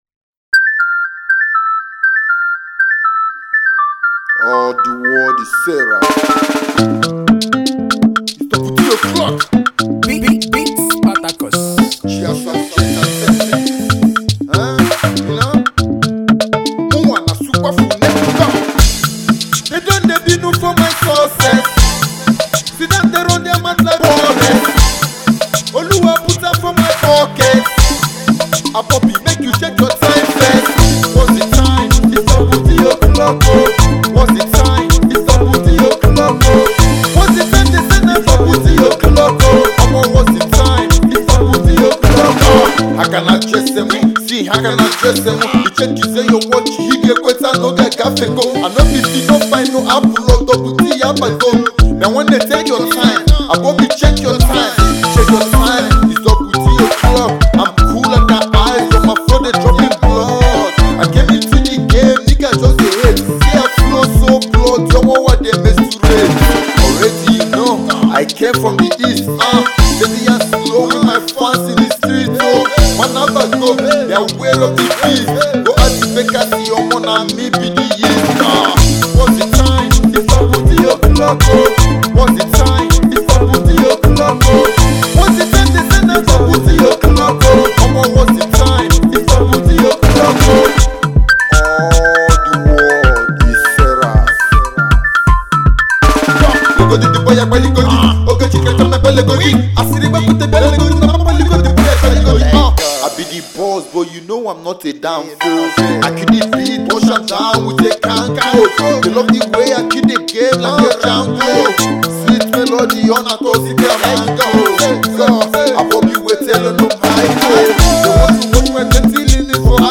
Port Harcourt indigenous rapper